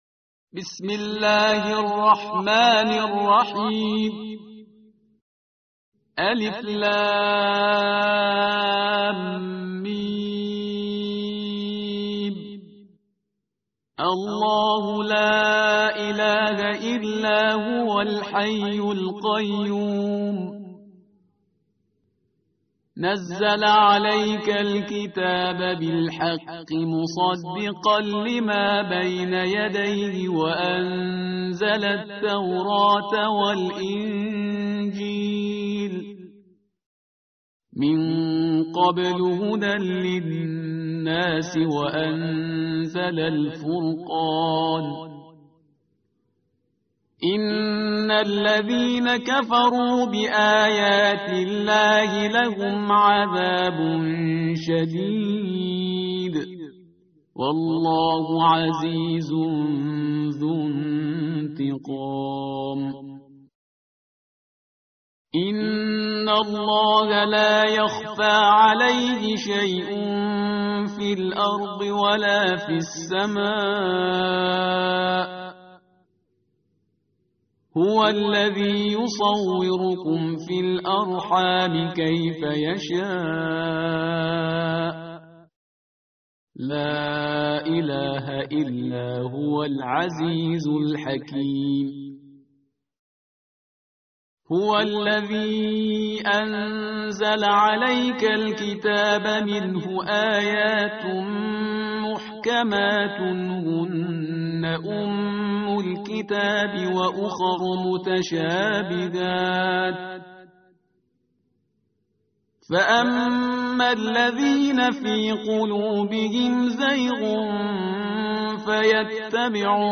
tartil_parhizgar_page_050.mp3